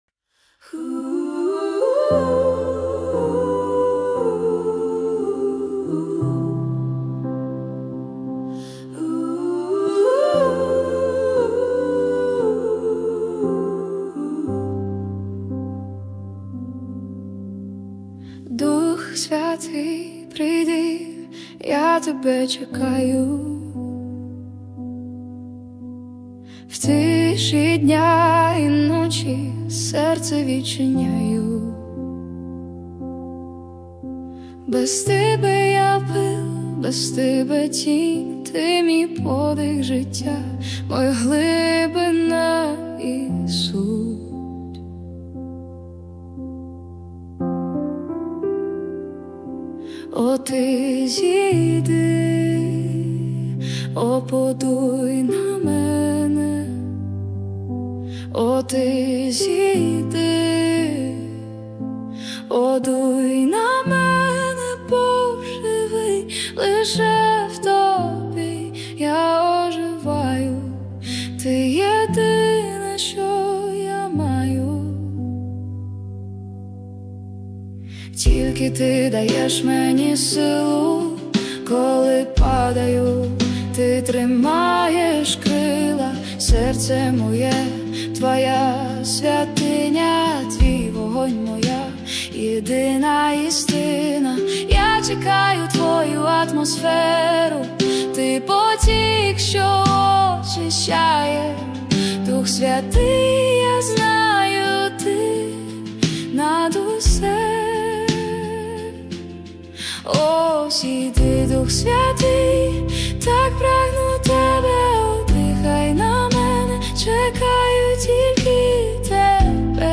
песня ai
307 просмотров 381 прослушиваний 37 скачиваний BPM: 176